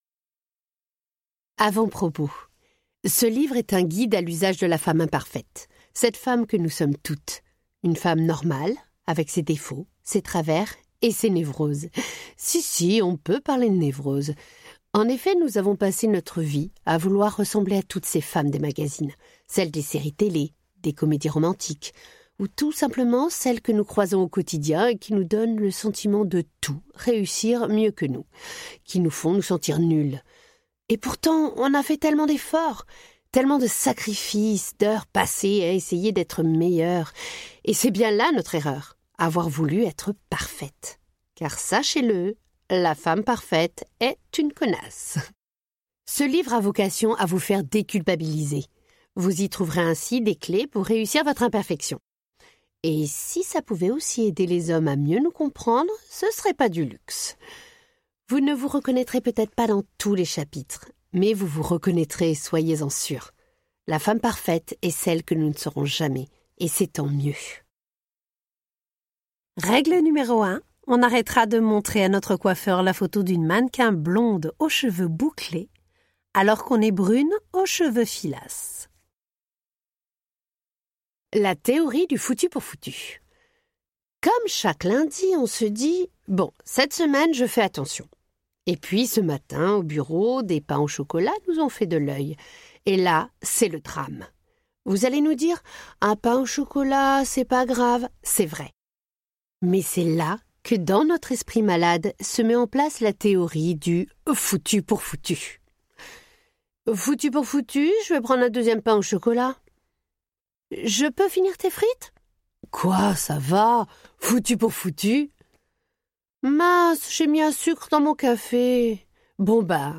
Extrait gratuit - La femme parfaite est une connasse ! de Anne-Sophie Girard, Marie-Aldine Girard